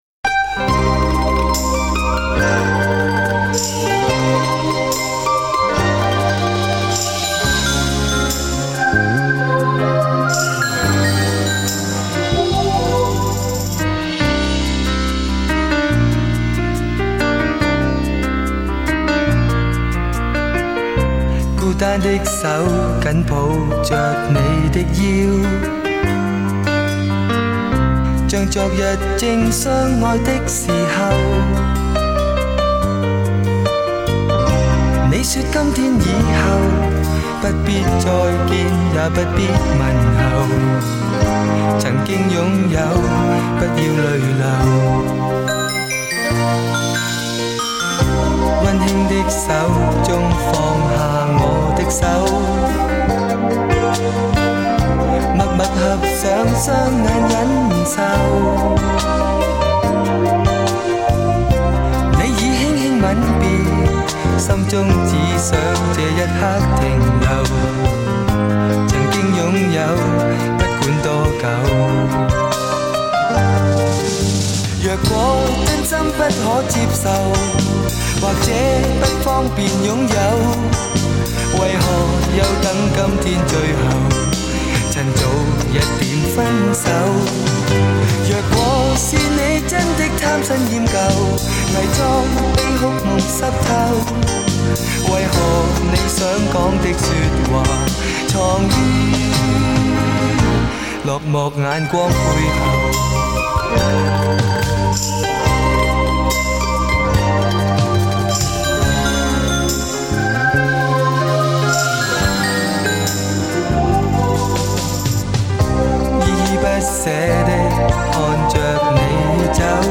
在他的歌里能感受到茫然和低落。
高密度24BIT数码录音